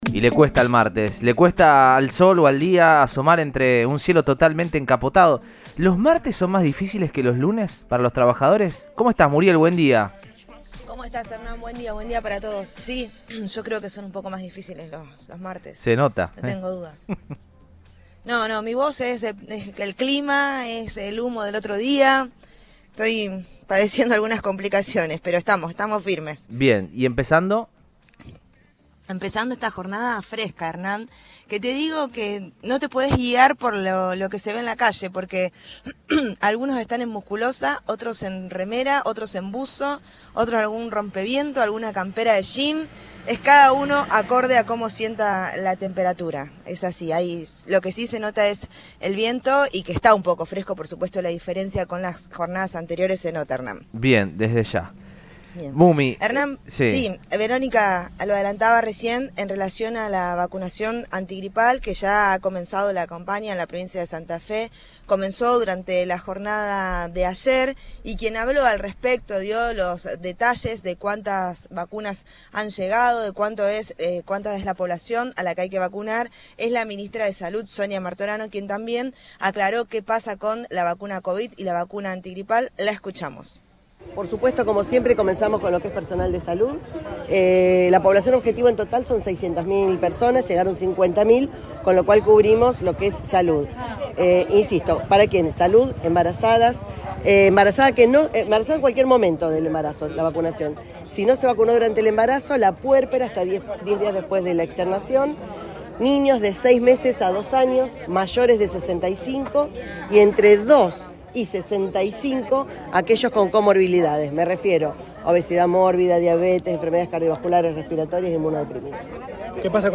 Sonia Martorano, ministra de salud de la provincia de Santa Fe, dio detalles de la campaña de vacunación antigripal al móvil de Cadena 3 Rosario, en Radioinforme 3.